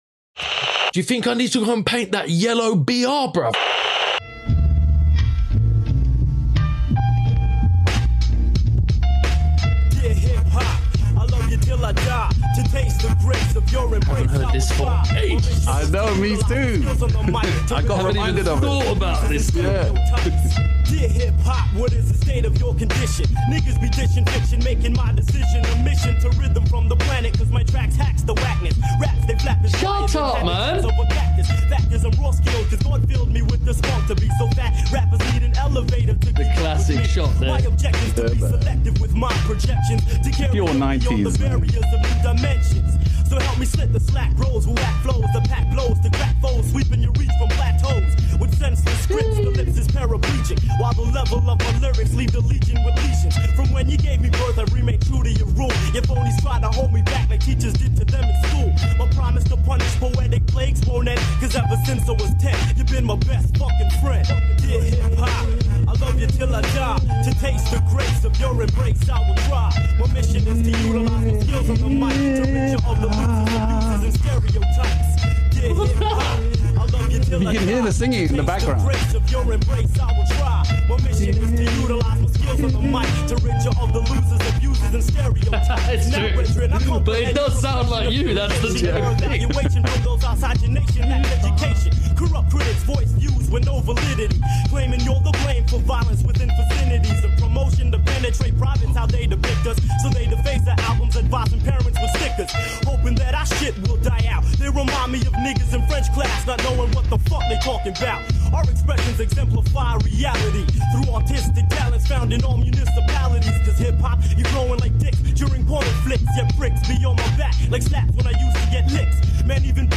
This week the lads find some of their favourite messages written next to Graffiti... Music, Graff Letter Game and Critique as usual too....